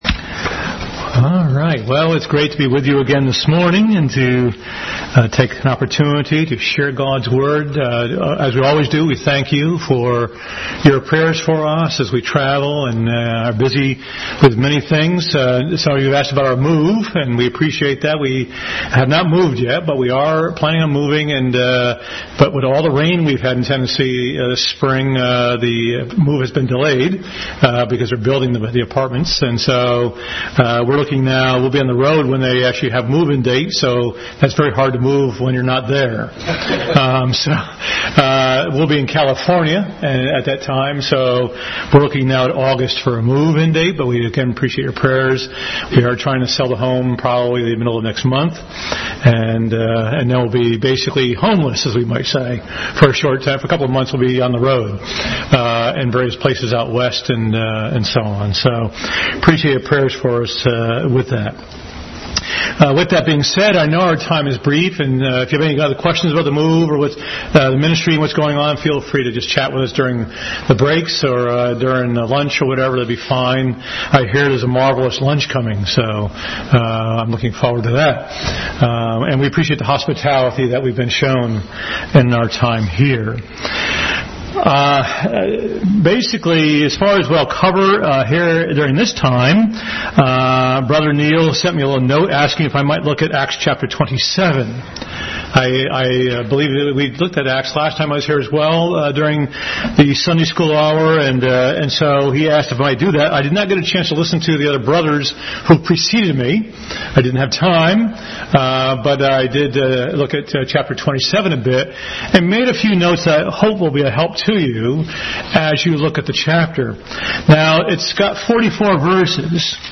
Bible Text: Acts 27:1-38 | Adult Sunday School regular study in the book of acts.